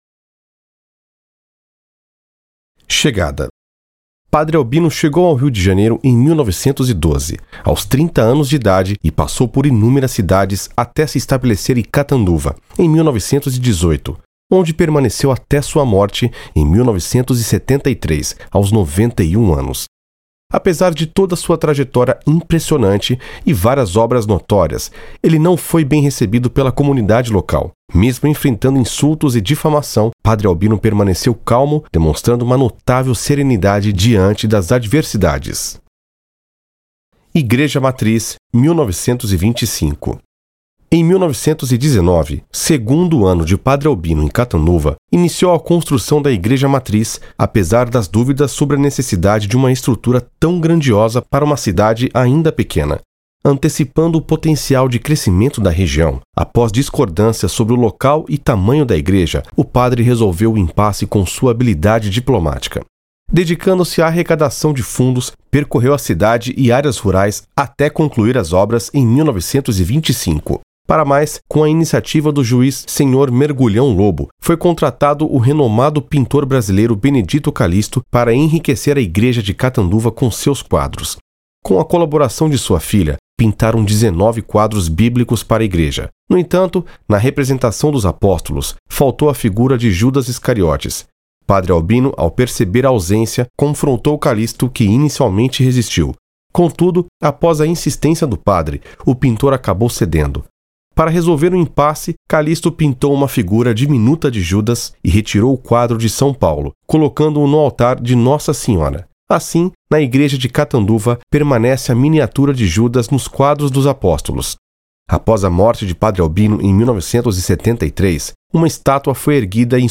PROJETO-Padre-Albino-Doc-LOCUCAO.mp3